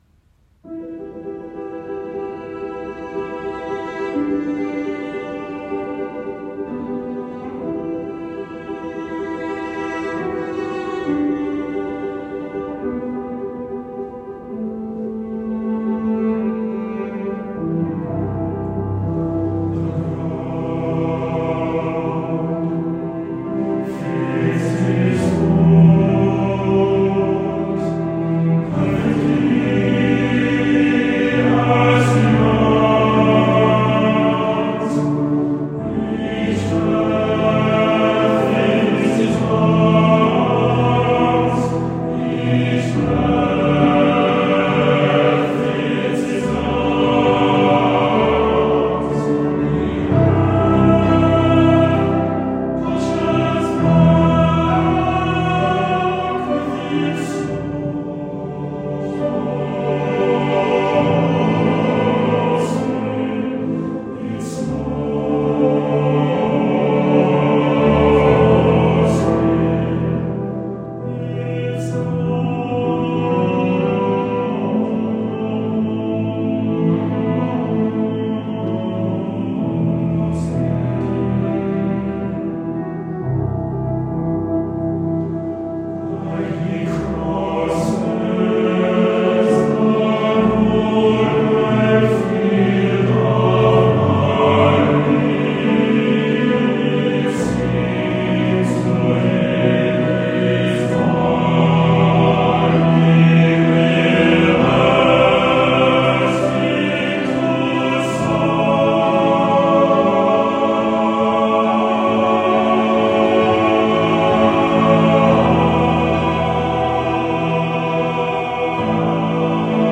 Voicing: "TTBB"